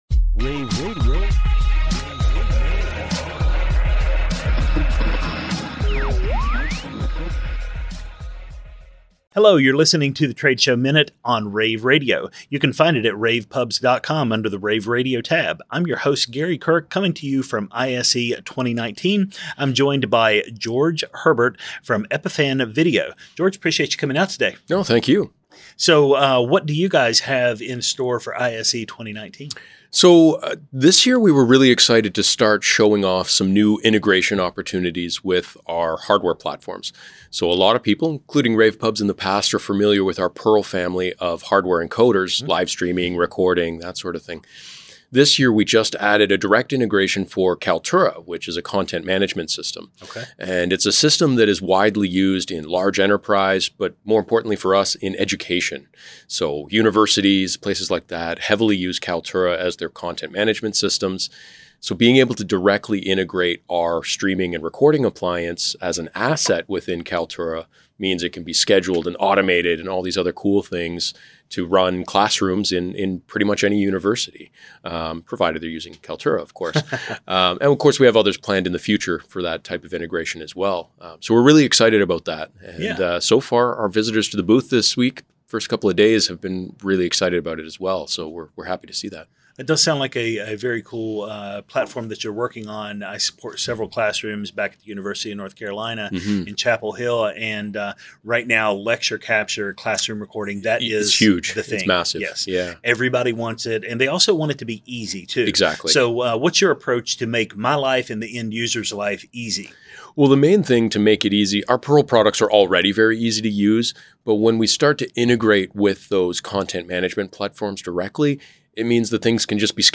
interviews
February 6, 2019 - ISE, ISE Radio, Radio, rAVe [PUBS], The Trade Show Minute,